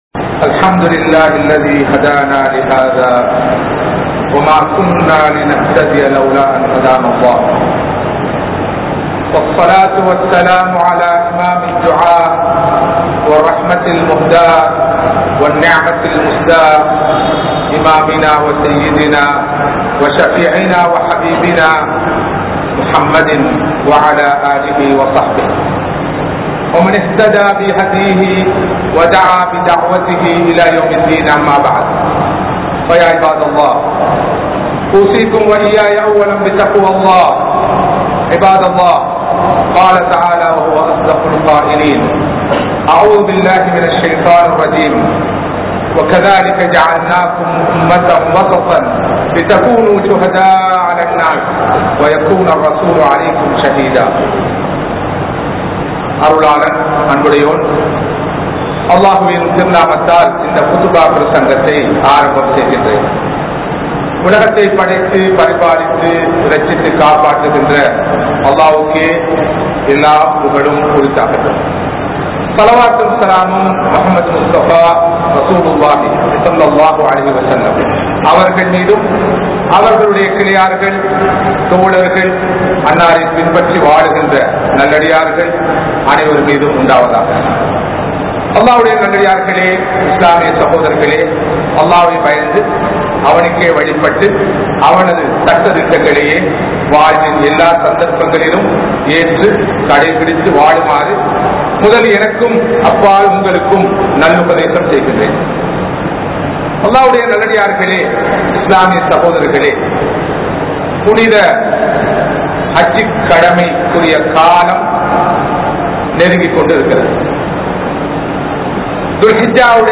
Moderation In ISLAM | Audio Bayans | All Ceylon Muslim Youth Community | Addalaichenai
Colombo 03, Kollupitty Jumua Masjith